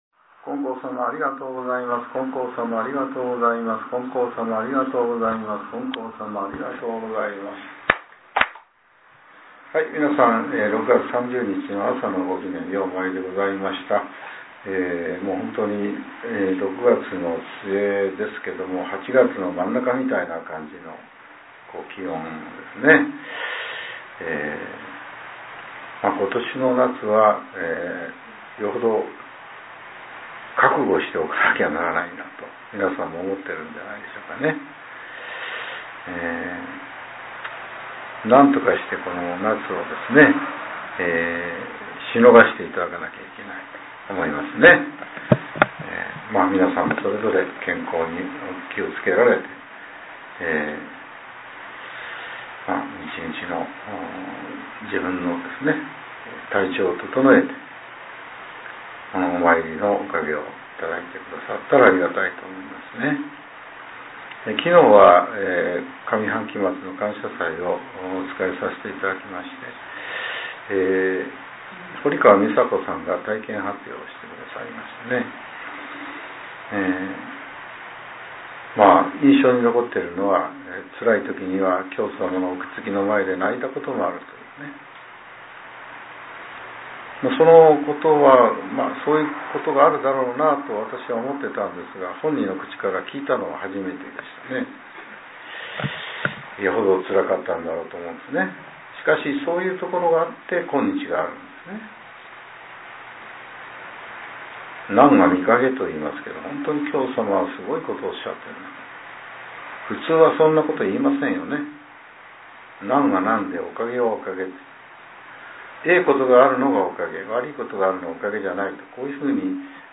令和７年６月３０日（朝）のお話が、音声ブログとして更新させれています。